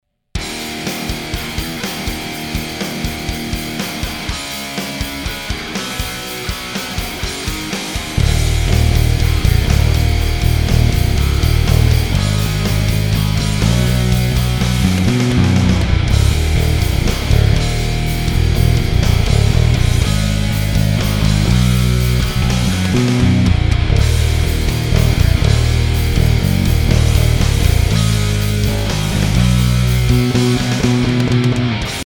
Der Ethos HP 5 hat nun auch die Superbrights spendiert bekommen, nachdem die H Saite beim 130er NYXL Satz eher enttäuscht hatte. H-Saite klingt wieder geil.